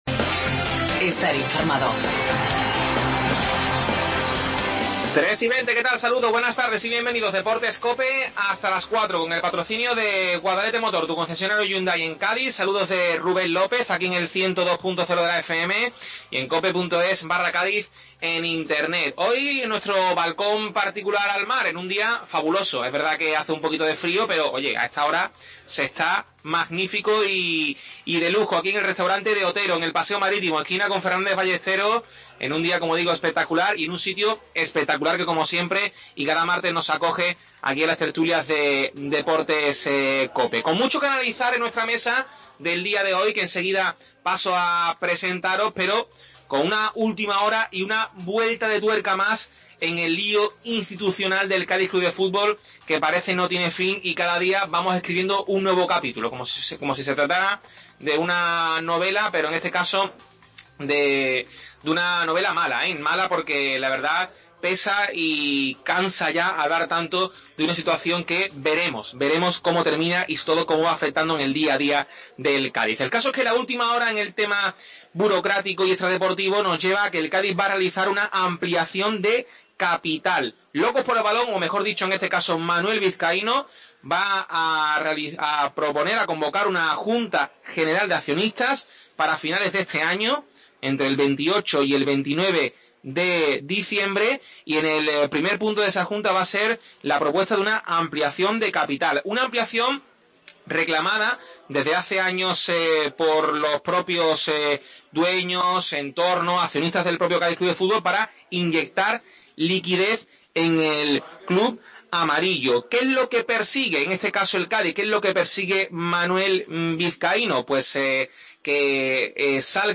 Desde el Restaurante De Otero tertulia